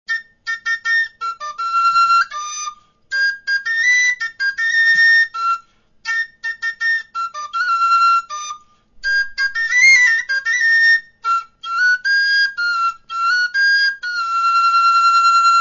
LA FLAUTA MARAGATA
Los ejercicios de esta sección, también en su mayoría tradicionales de León, se han realizado con la flauta “Maragatina”, cuya nota básica en digitación cerrada (LA) suena también como LA, aunque tiende a quedar un poco alta.
Las grabaciones no pretenden mostrar calidad sonora ni interpretativa, y deben tomarse como simples referencias didácticas ilustrativas.
EJECUCIÓN EN EL MODO DE MI
Tónica en MI
Es una tónica ya un poco alta, con la dificultad añadida del FA natural.